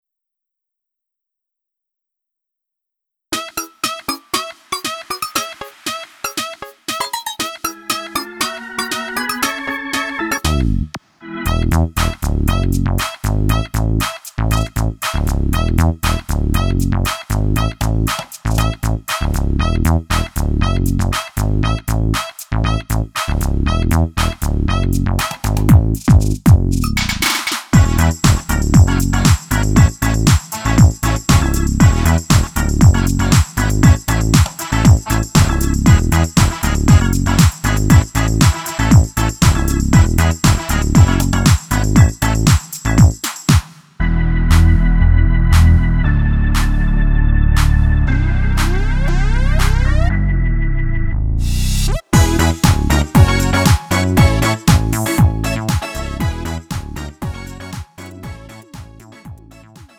음정 원키 3:20
장르 가요 구분 Lite MR